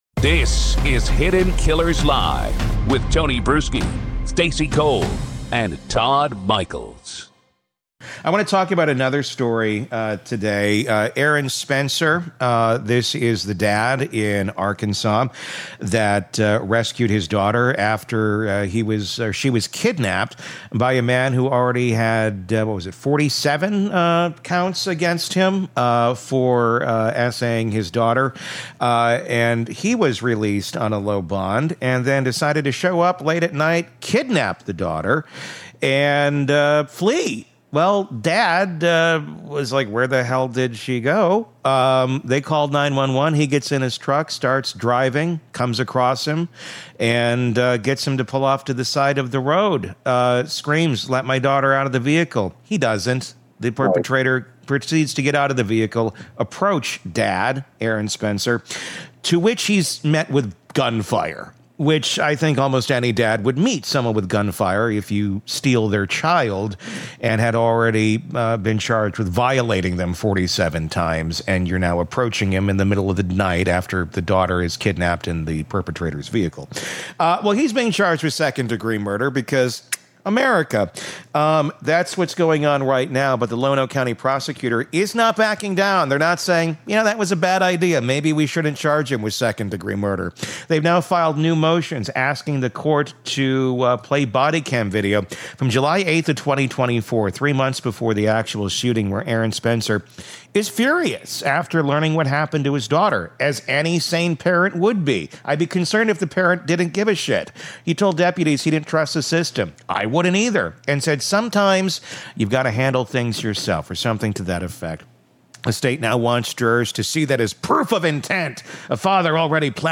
In this Hidden Killers interview